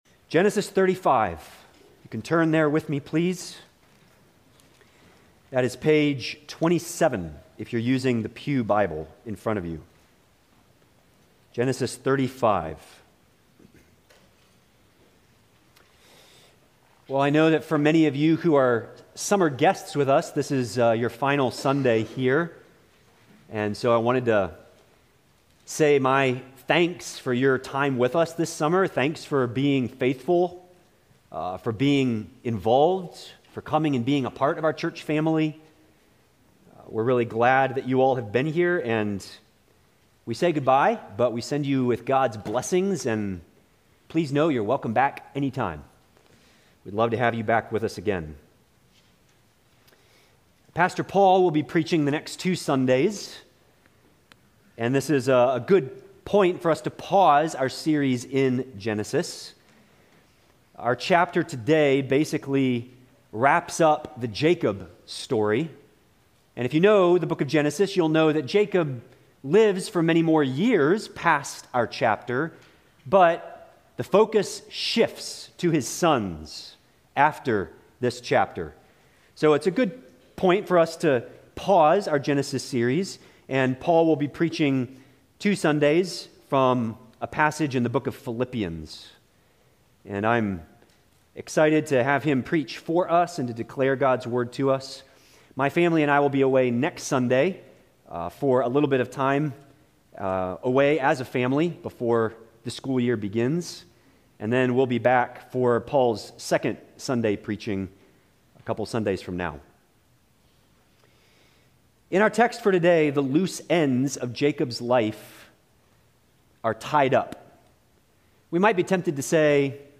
Sermons – Bethany Baptist Church Brevard, NC